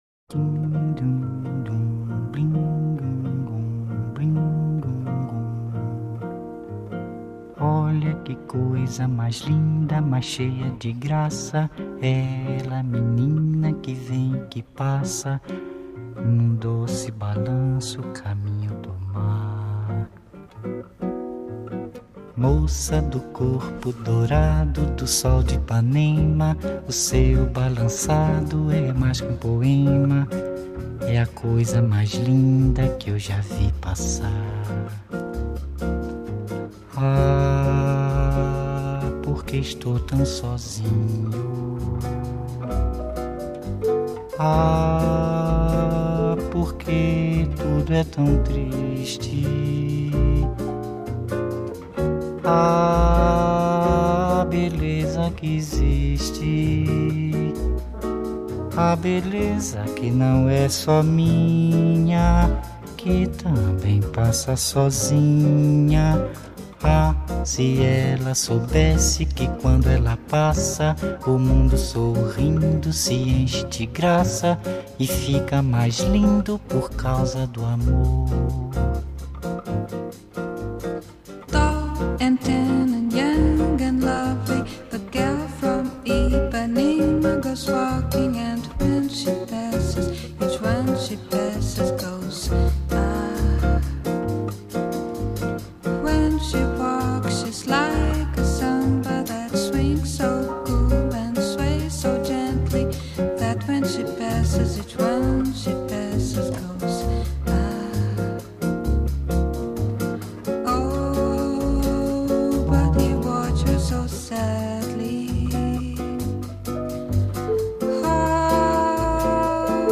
Genre: Bossa Nova